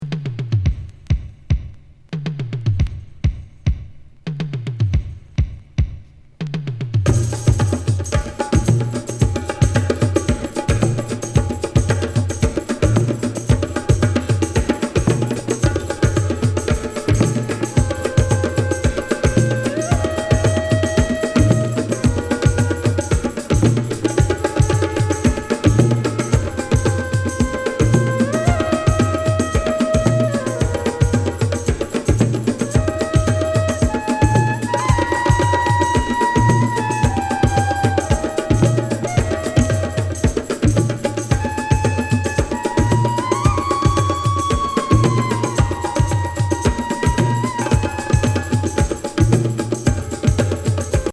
フランス産ライブラリー的オブスキュア盤！エスノ・パーカッシブ・サイケ・グルーヴ